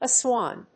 音節As・wan 発音記号・読み方
/ɑːsάːn(米国英語)/